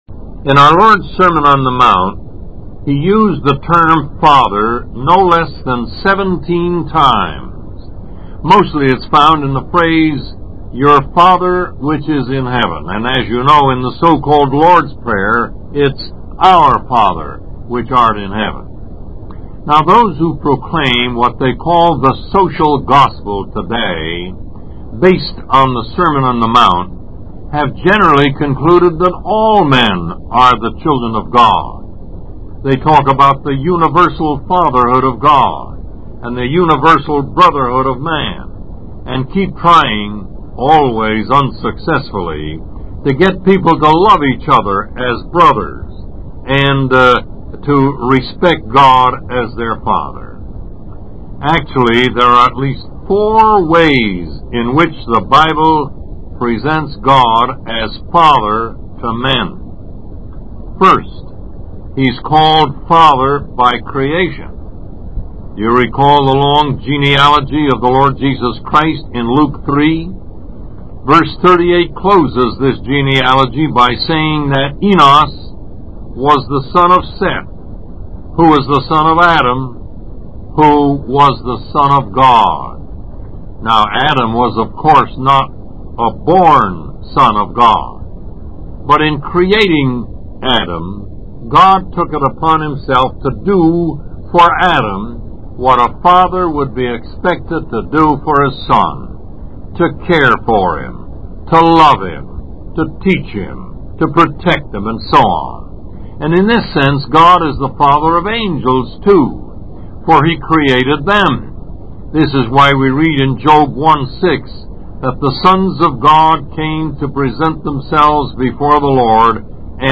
Lesson 7: The Fatherhood of God